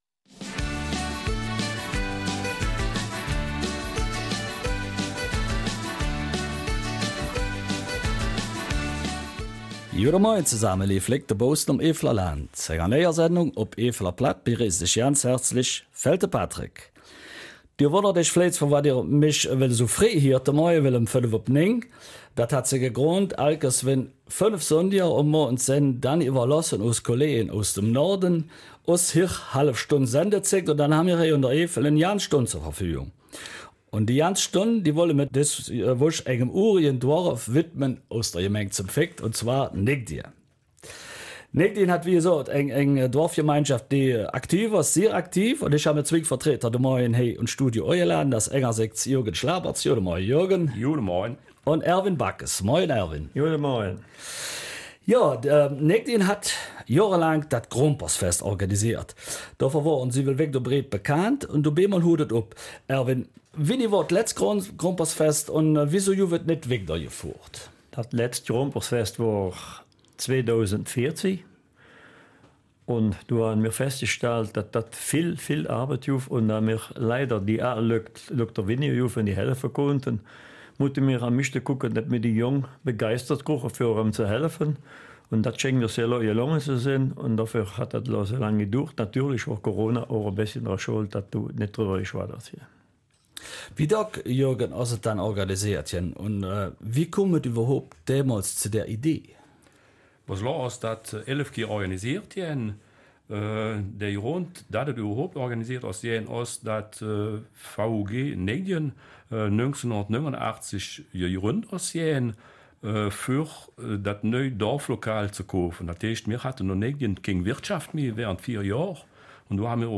Eifeler Mundart: Ortschaft Neidingen